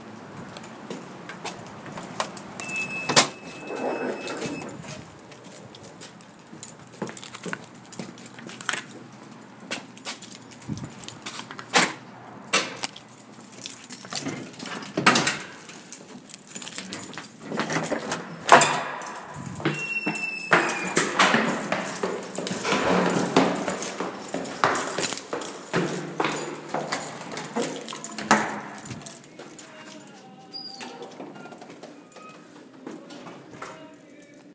Field Recording 4
Tower Enter Swipe, door opening, footsteps, turnstyle moving, door opening, singing
Swipe, door opening, footsteps, turnstyle moving, door opening, singing